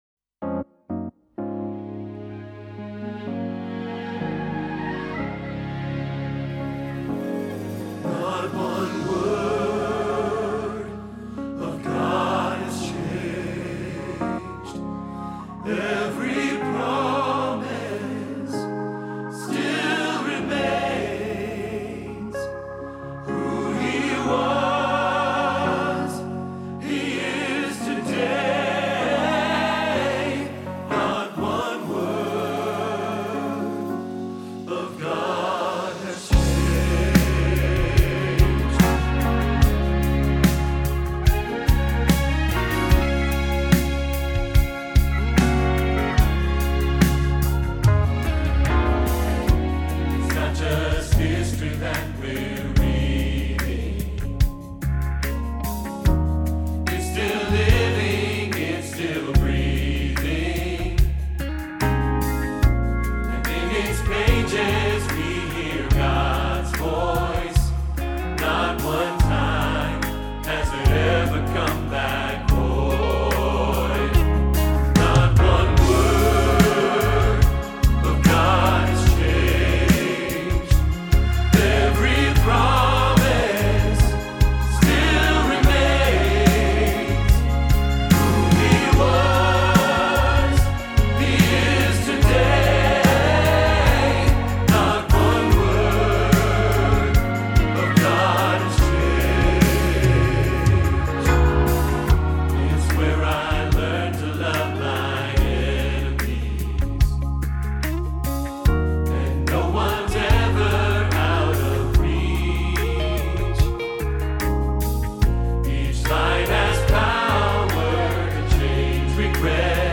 Not One Word – Soprano Hilltop Choir